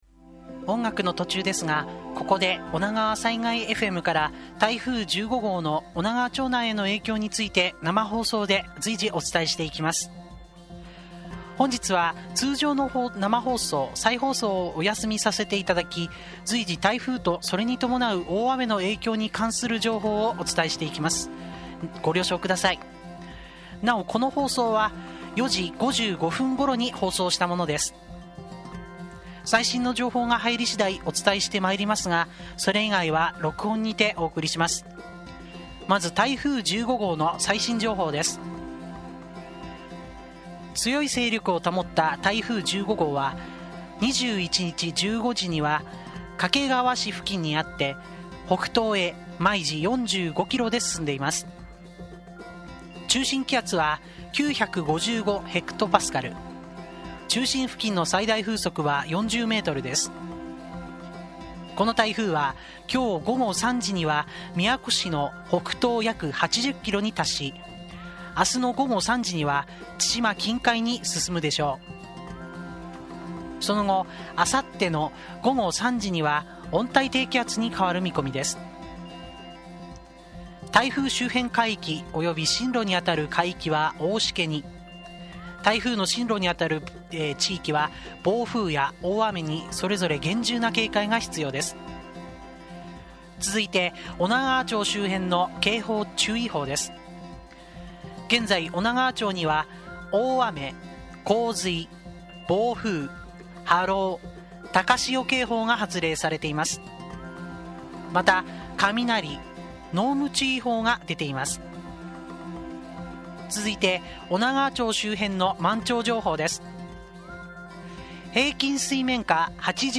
今回は当日の様子を知っていただくために、 ２１日夕方と２２日朝の放送の様子をポッドキャストでも公開いたします。